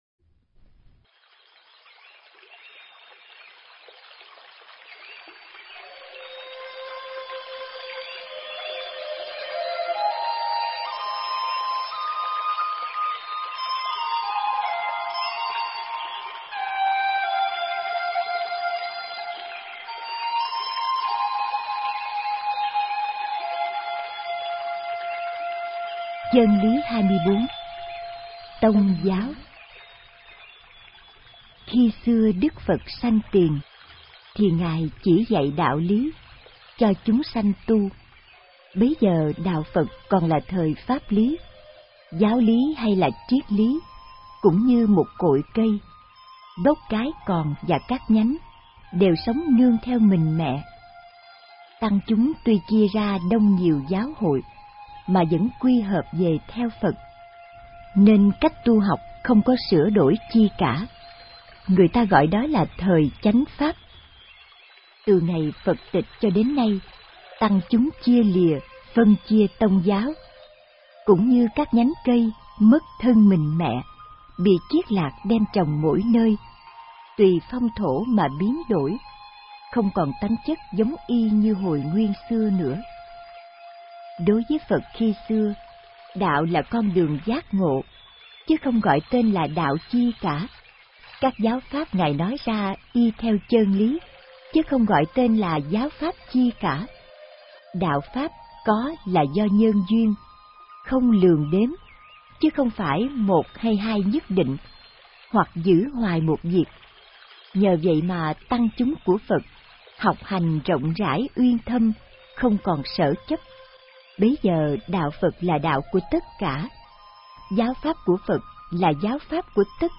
Nghe sách nói chương 24. Tông giáo